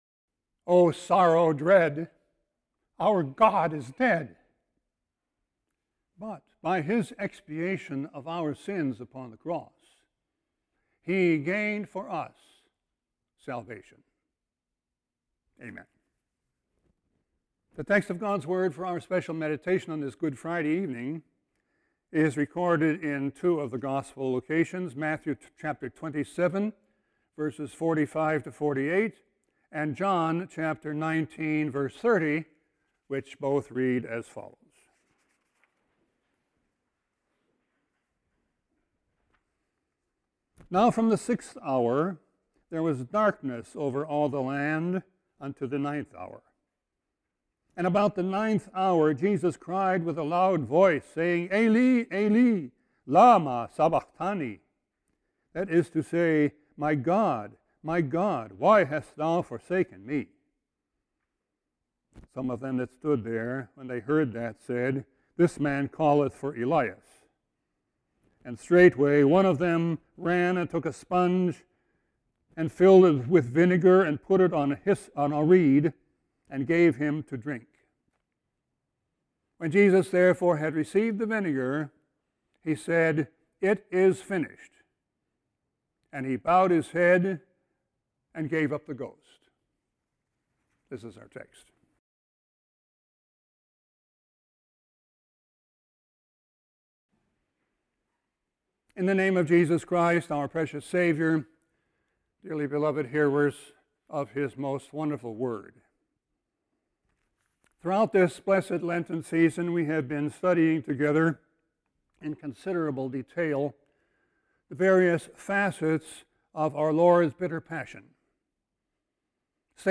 Sermon 4-15-22.mp3